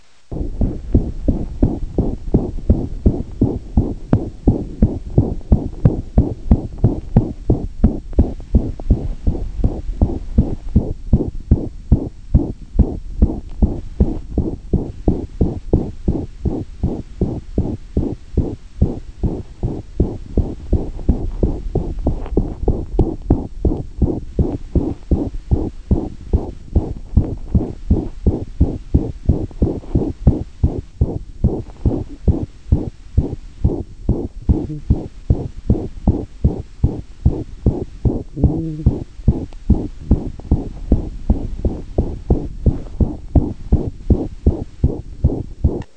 Klinische Untersuchung: Puls 100 Schläge pro Minute Rhythmus und peripherer Puls unregelmäßig Herzauskultation Datei 1.981 KB